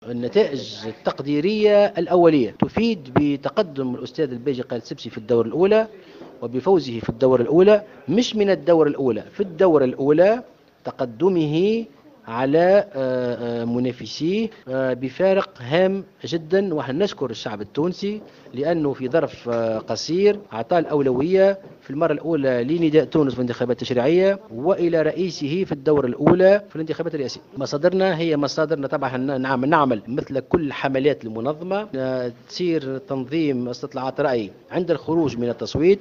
Mohsen Marzouki, président de la campagne électorale du candidat Beji Caied Sebsi, a déclaré ce dimanche 23 novembre 2014 lors d’une conférence de presse que selon les résultats préliminaires, BCE vient en tête des votes.